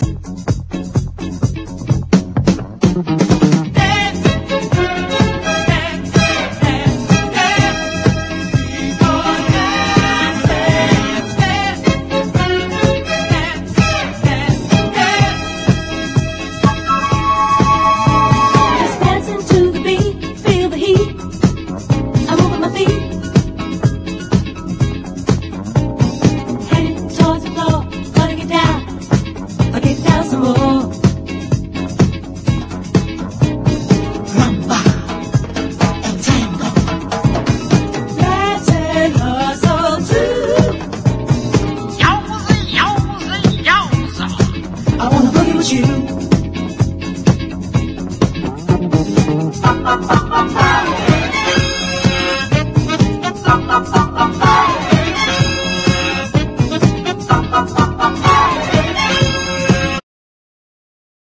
SOUL / SOUL / 70'S～ / DISCO / DANCE CLASSIC / 90'S
90年代の楽曲をカヴァーして、よりノスタルジックさ漂うディスコ・サウンドにアレンジされているのがサイコー！